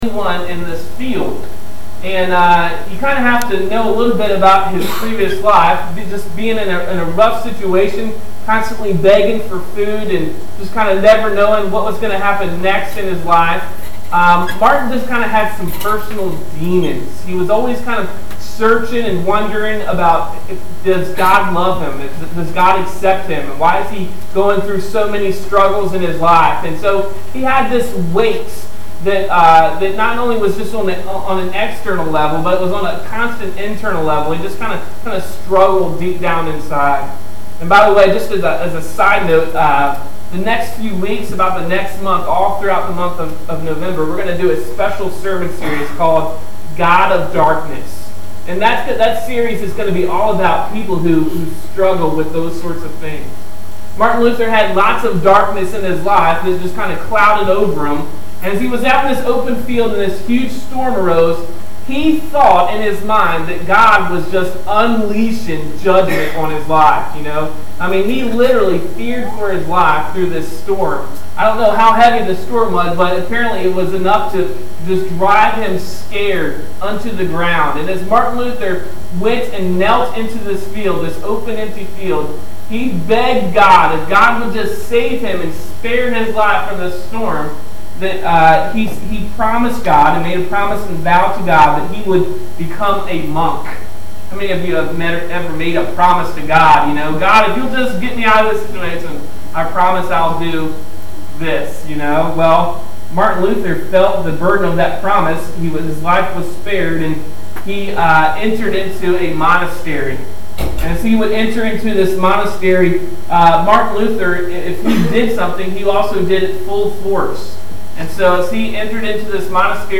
Today’s message will examine the Life of Martin Luther and what sparked him to become a reformer.[1]